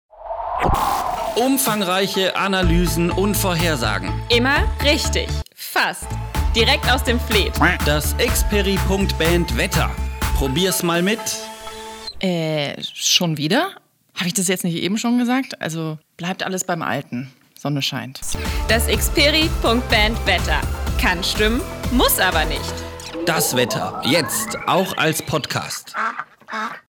Das Wetter zum Ausprobieren (jetzt auch als Podcast)
Wetter-Trailer-v2.mp3